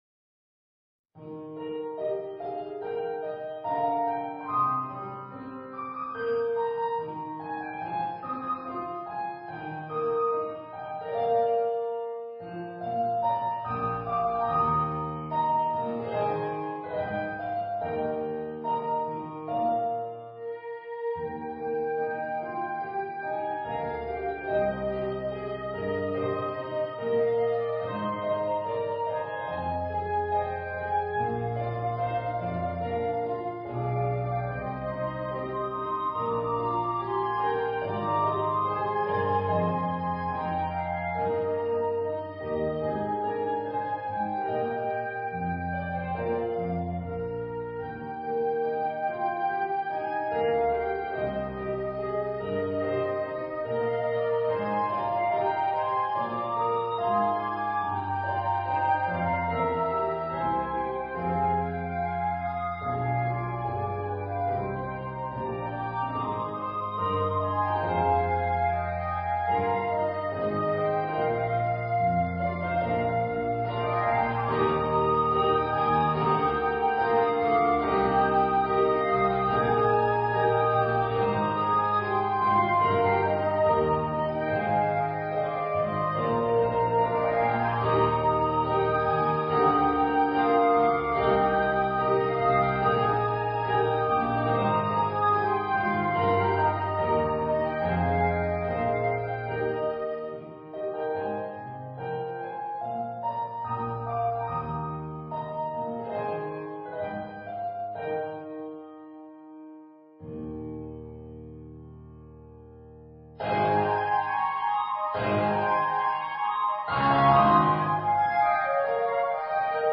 编制：Cl / Cl / Pno
B♭ Clarinet 1
B♭ Clarinet 2
Piano